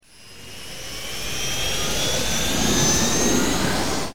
shipMove.wav